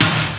PHL SNR 1.wav